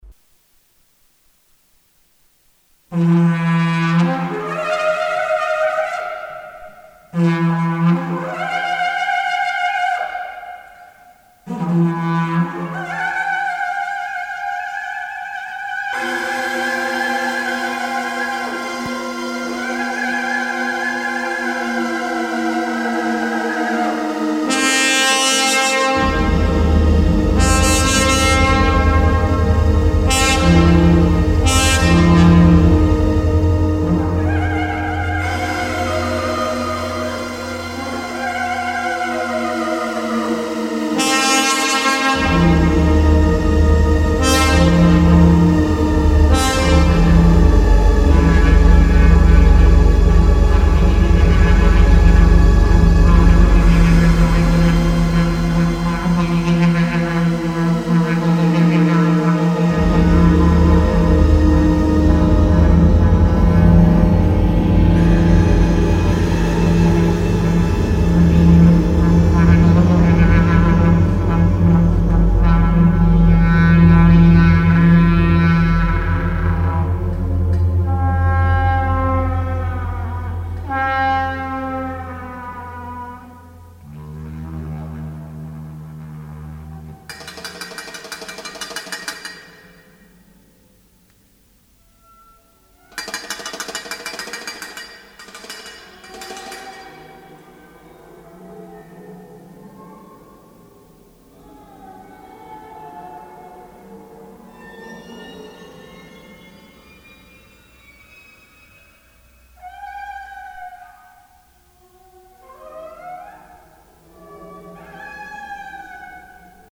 from a live performance in Aberdeen 08
This means there are two independent time-streams of music.